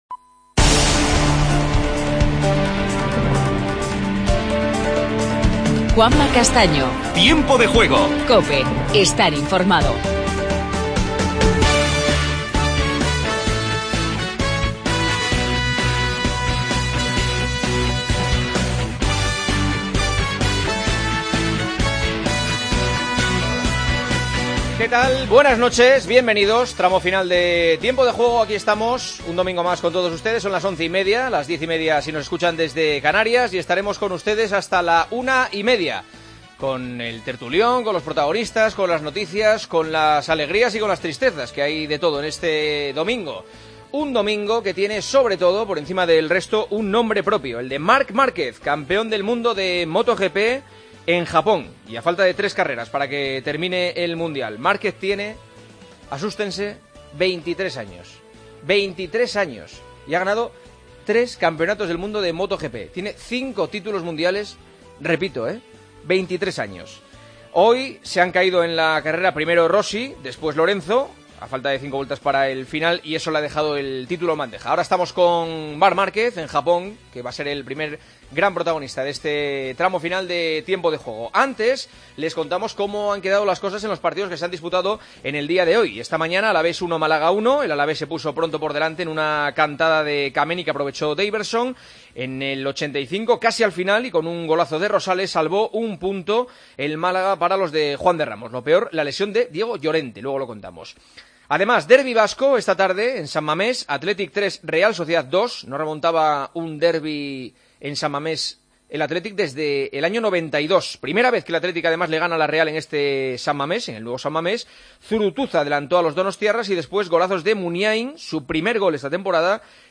Entrevista a Marc Márquez, pentacampeón del mundo de motociclismo. El Villarreal ganó 5-0 al Celta....
Entrevista a Fran Escribá.